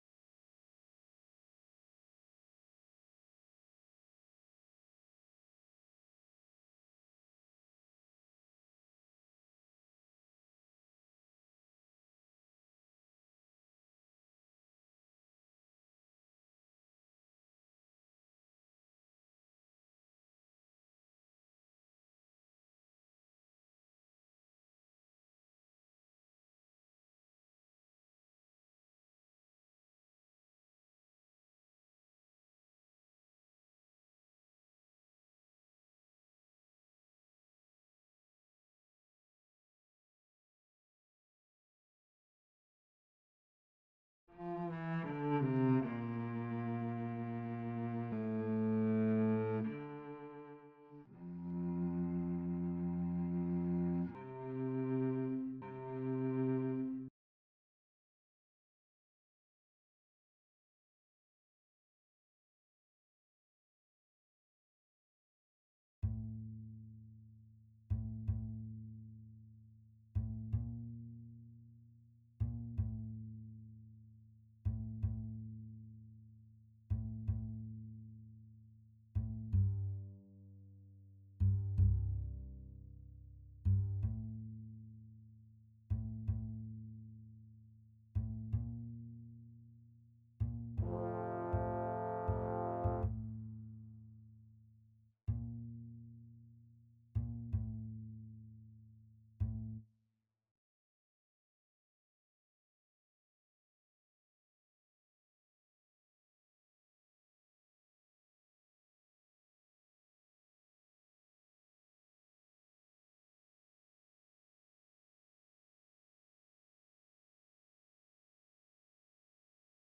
” is a modernly classic, invitational choir composition